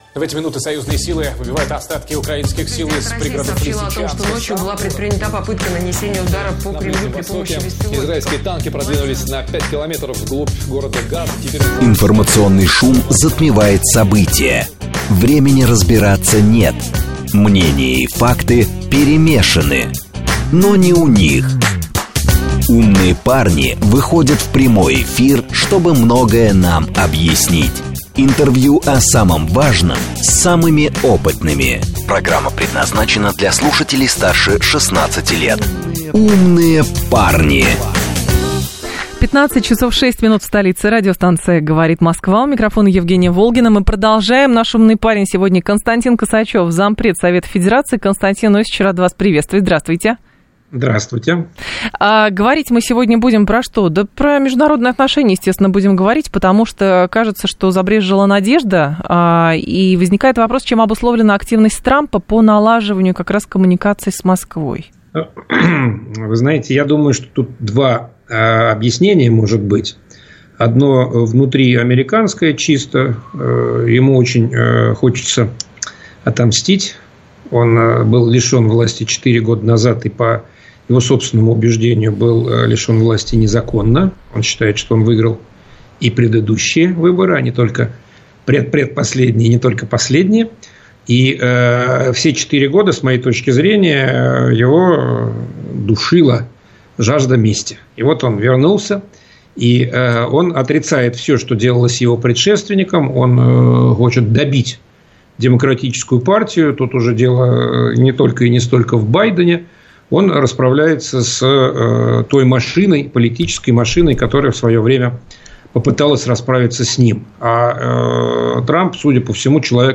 Зампред Совета Федерации РФ Константин Косачёв в программе «Умные парни» от 18.03.25